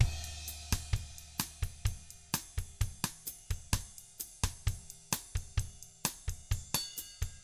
129BOSSAT1-R.wav